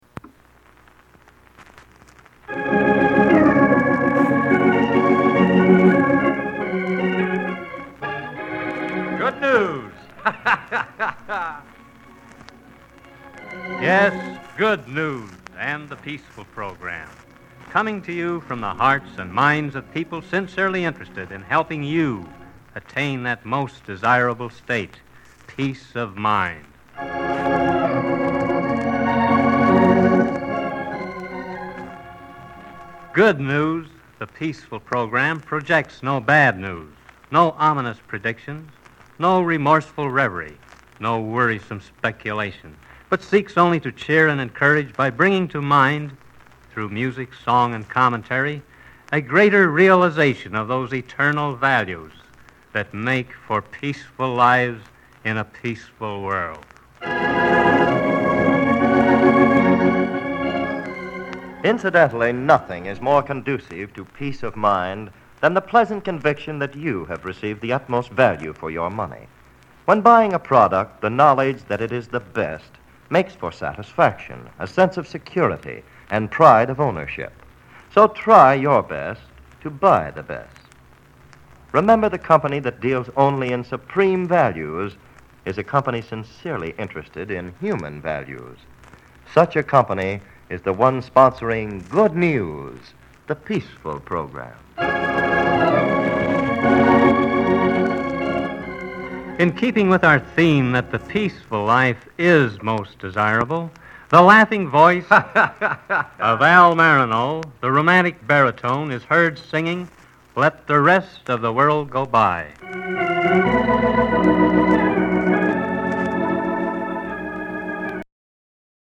Peace Good News Quality Products World War Organ